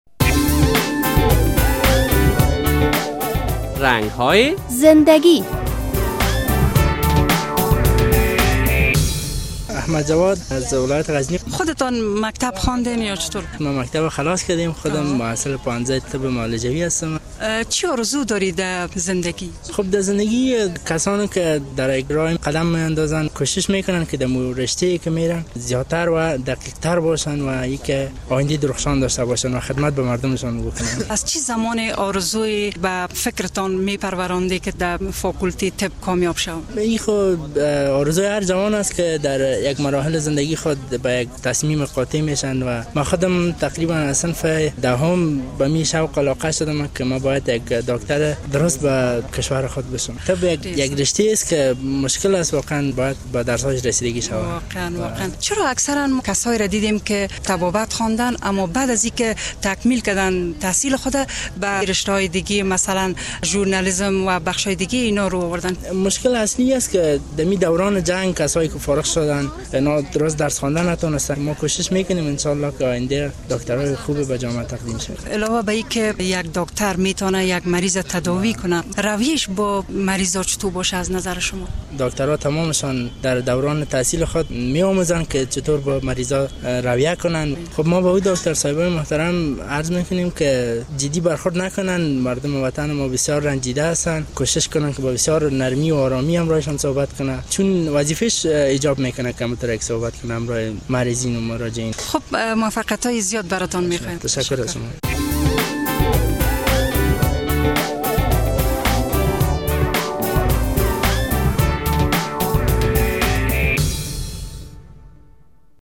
هر جوان افغان در آوان جوانی آرزو های دارد که وضعیت افغانستان گاهی مانع رسیدن آنها شده و گاهی جوانان افغان با همین امکانات می توانند به اهداف شان برسند و این بار با یک تن از محصلین پوهنتون طبی کابل مصاحبه کرده ایم: